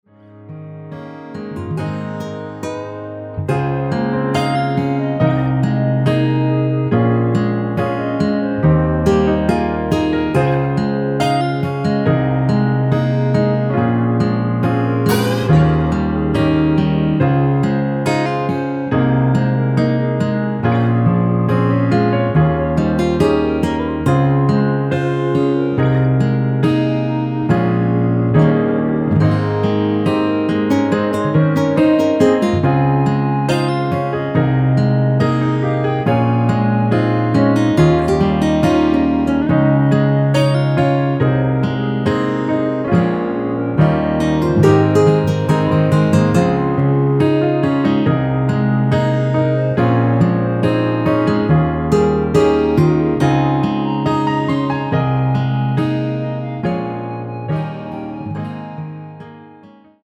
원키에서(-2)내린 멜로디 포함된 MR 입니다.(미리듣기 참조)
Ab
앞부분30초, 뒷부분30초씩 편집해서 올려 드리고 있습니다.